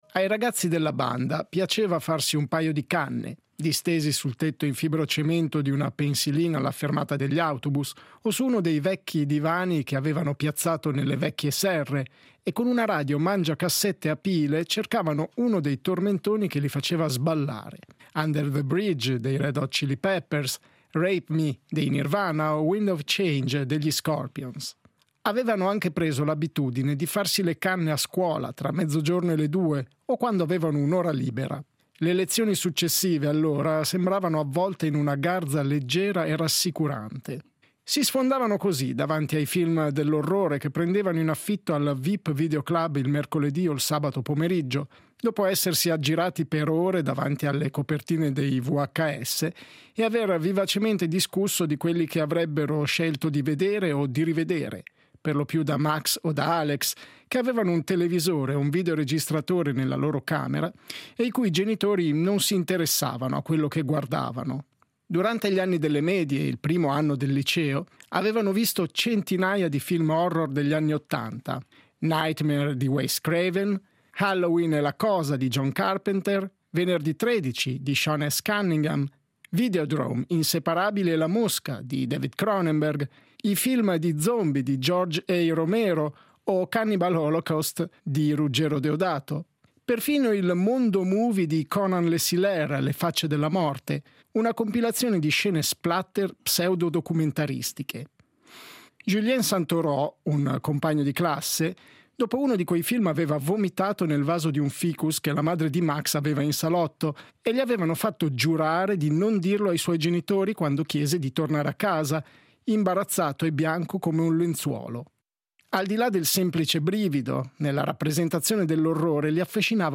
Intervista a Jean-Baptiste Del Amo